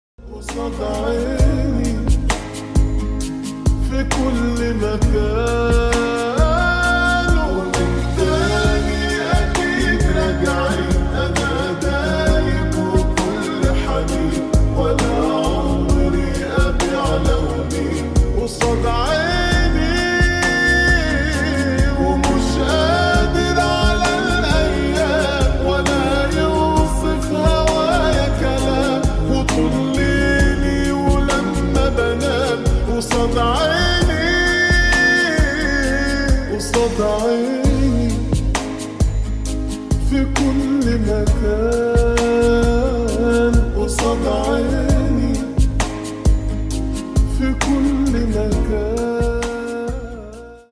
Trending Ai Girl Effect ✅ sound effects free download